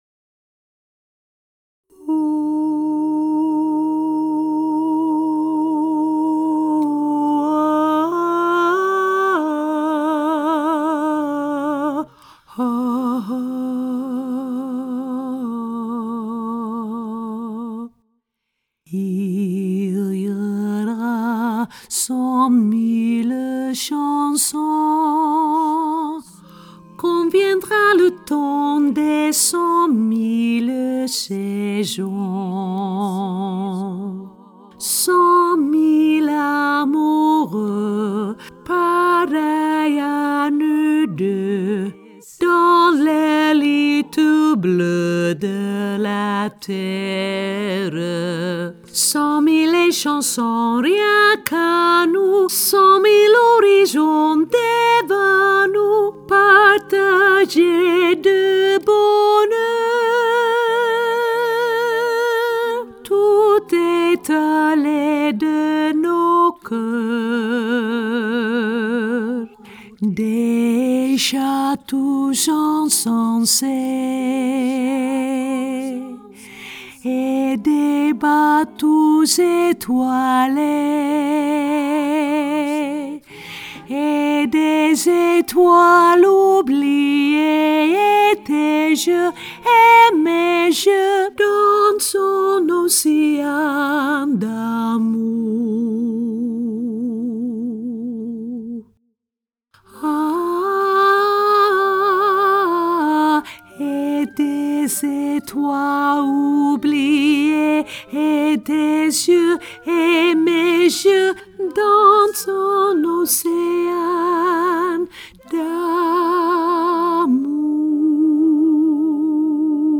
alt laag